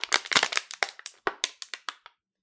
塑料水瓶吹起来的
描述：塑料水瓶的声音被吹入膨胀。 此文件已标准化，大部分背景噪音已删除。
Tag: 塑料 紧缩 粉碎